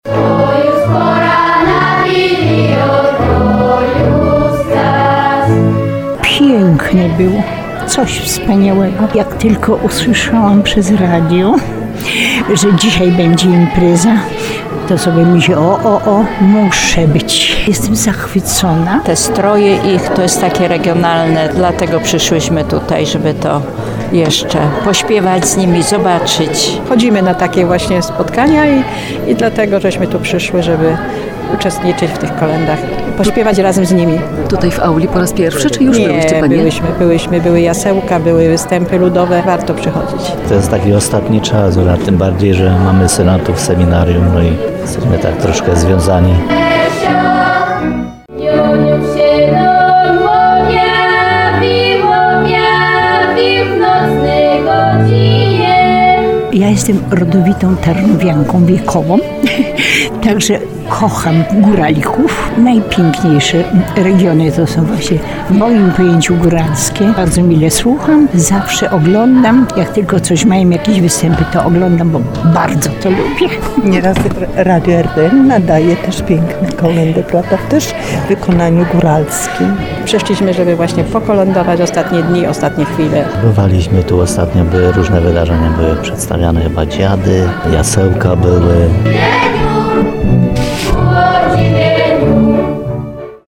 Kolędy i pastorałki na góralską nutę wybrzmiały w Wyższym Seminarium Duchownym w Tarnowie. W auli wystąpił Zespół Regionalny Zasadnioki. Grupa, którą tworzą dorośli, młodzież i dzieci, pielęgnuje tradycje Górali Białych od Kamienicy. Publiczność doceniła ich talent i regionalne stroje.